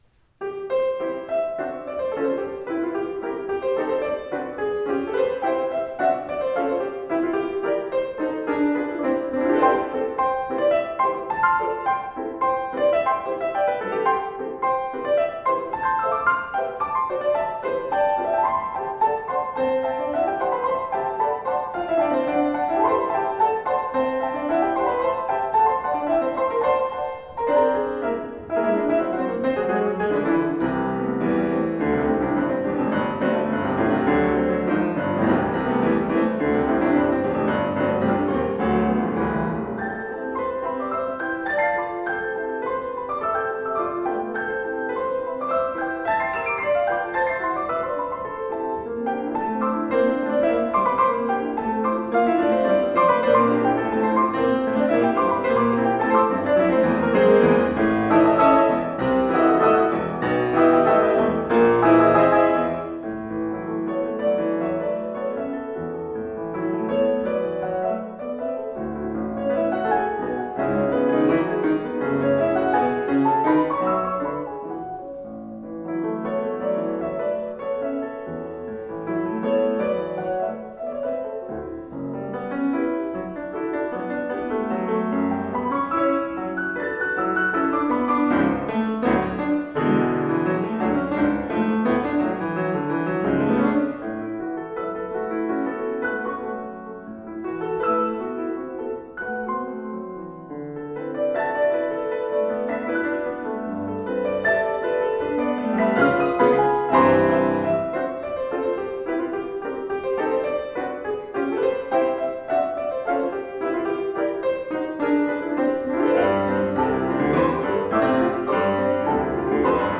Music of Edward Joseph Collins CD Anthology Release Celebration Roosevelt University Chicago
works for two pianos
pianists
6CowboyBreakdownfor2pfby.m4a